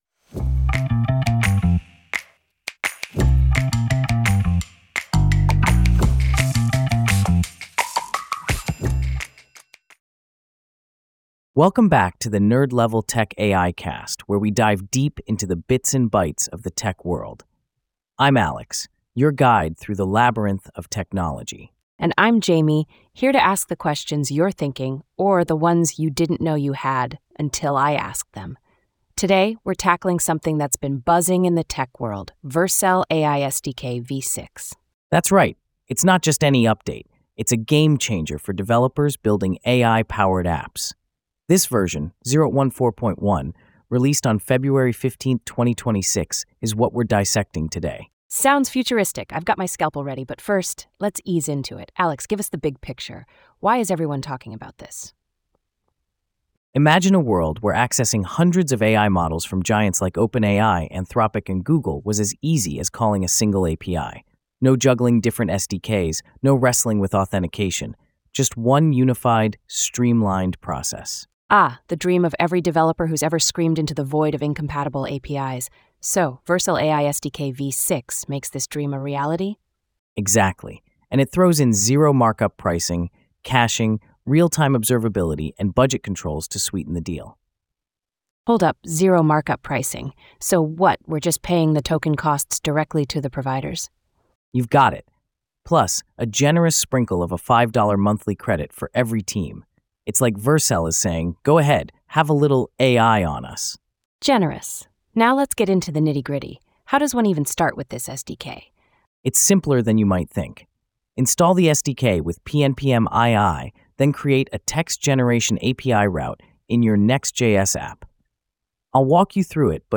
ai-generated